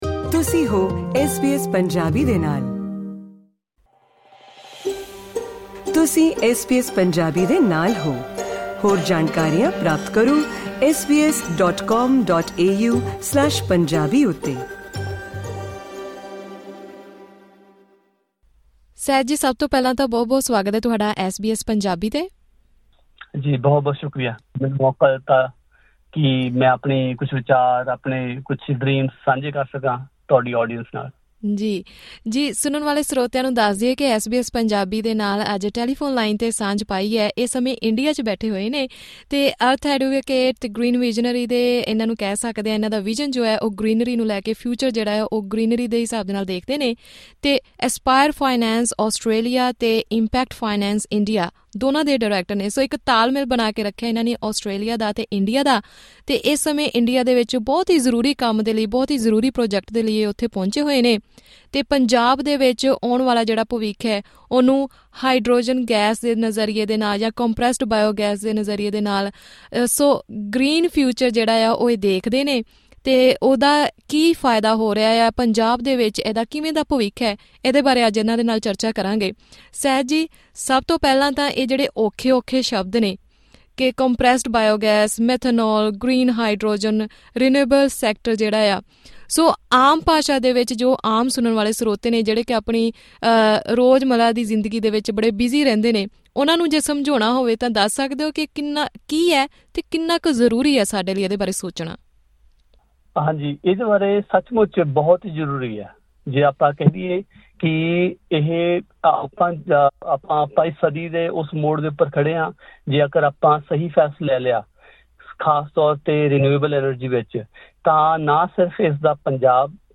ਹੋਰ ਵੇਰਵੇ ਲਈ ਸੁਣੋ ਇਹ ਗੱਲਬਾਤ ...